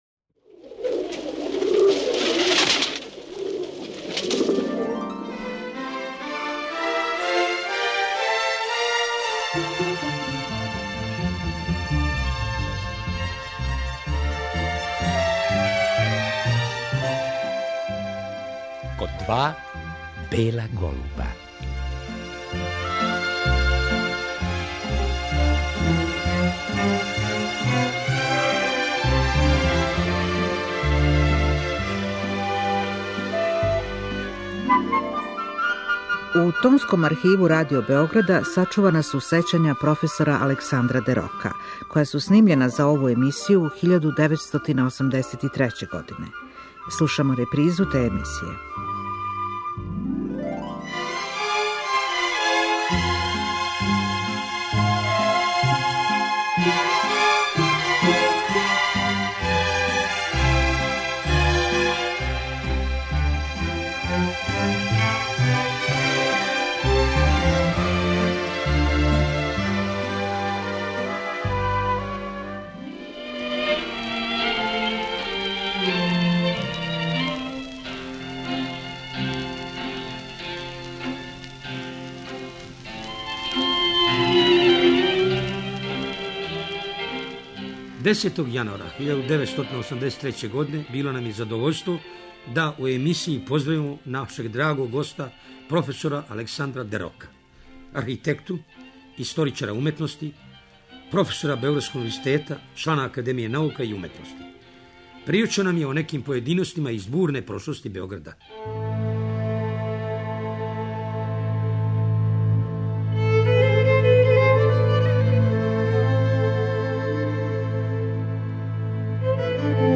У вечерашњој емисији чућемо сећања професора Дерока која су забележена 1983. године.